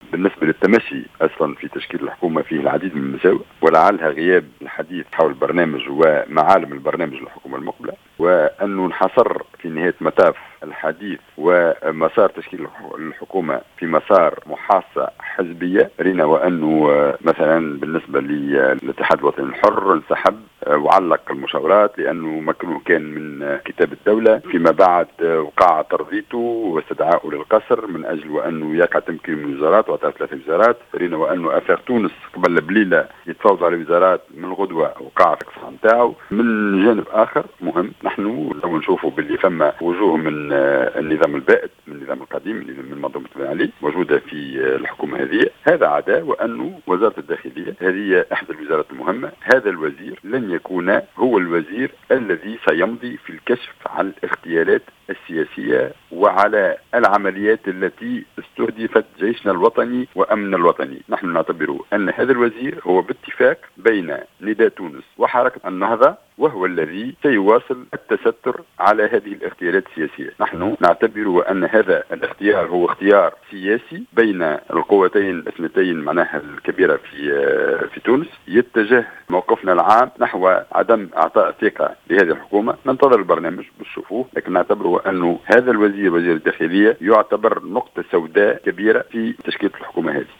قال نائب مجلس الشعب عن الجبهة الشعبية منجي الرحوي في تصريح ل"جوهرة أف أم" إن الجبهة الشعبية تتجه نحو عدم منح الثقة لحكومة الحبيب الصيد.